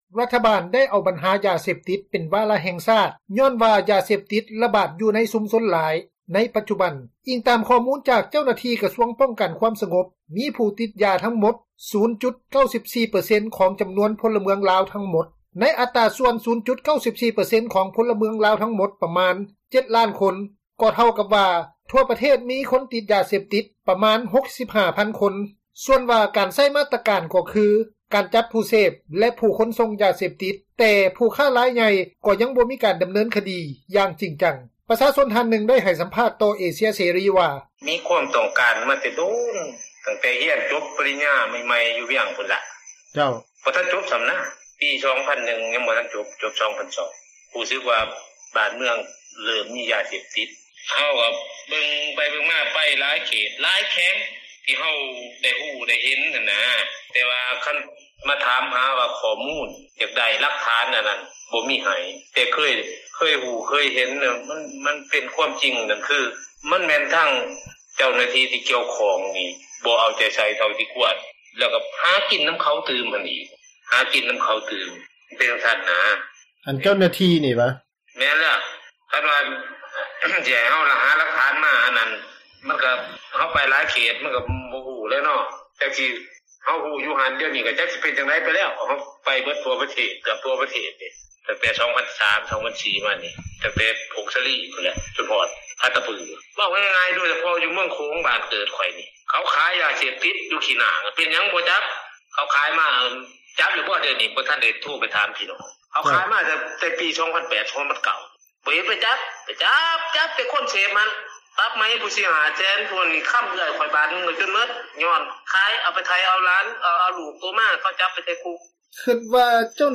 ປະຊາຊົນ ທ່ານນຶ່ງ ໄດ້ໃຫ້ສັມພາດຕໍ່ເອເຊັຽເສຣີ ວ່າ: